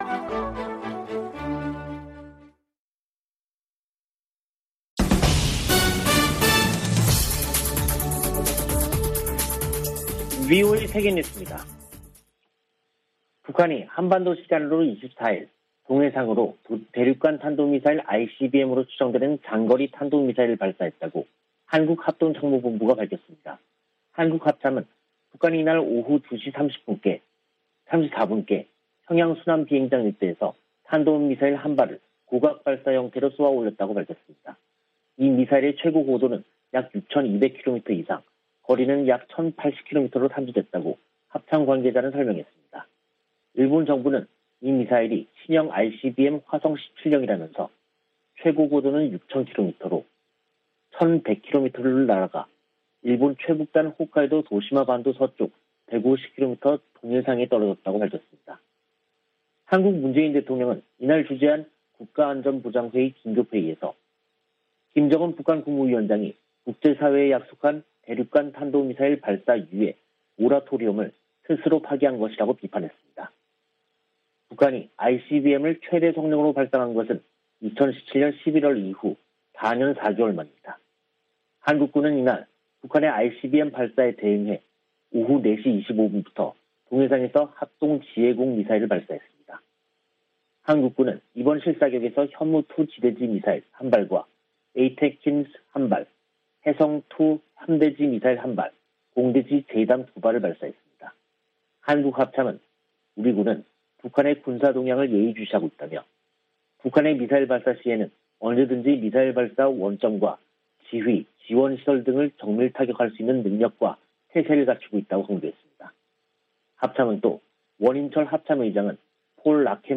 VOA 한국어 간판 뉴스 프로그램 '뉴스 투데이', 2022년 3월 24일 2부 방송입니다. 북한이 24일 대륙간탄도미사일(ICBM)으로 추정되는 미사일을 발사했습니다.